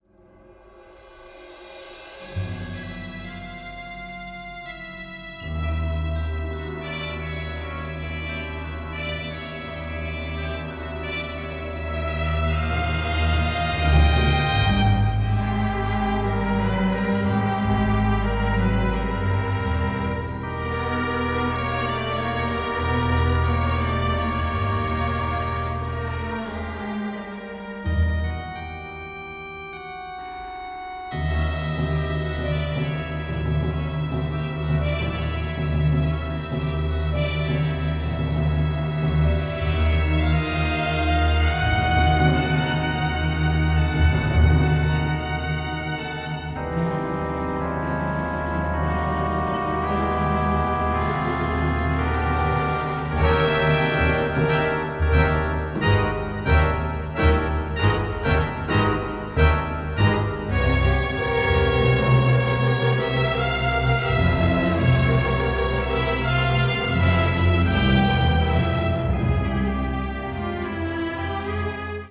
symphonic ensemble